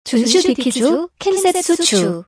Japanese Electronic Video Agent.